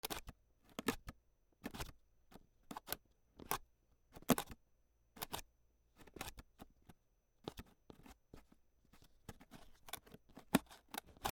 箱の耳を開ける
/ K｜フォーリー(開閉) / K56 ｜小物の開け閉め